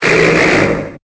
Cri de Téraclope dans Pokémon Épée et Bouclier.